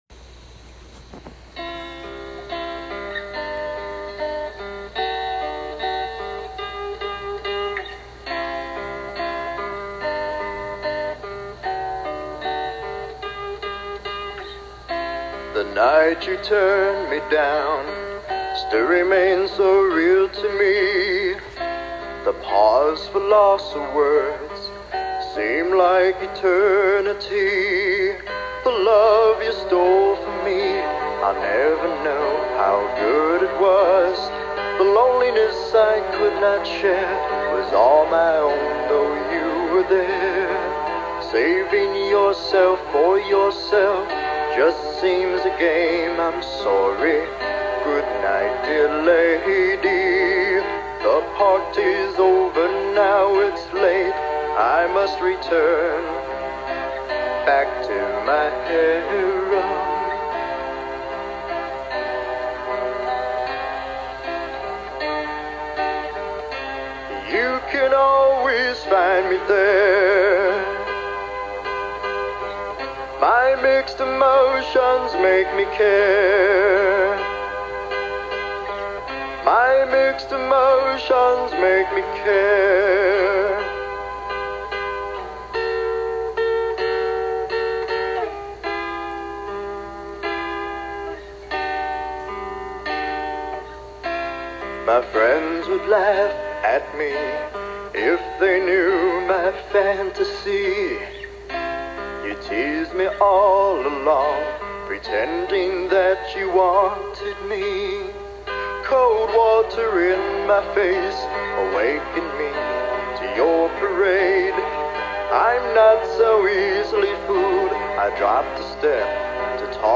It is primarily a testimony sermon, sharing the speaker's personal spiritual journey.